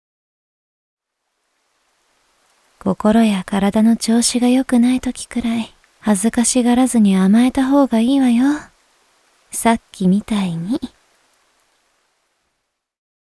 やさしい声は、いちばん効くおくすり💊
ボイスサンプル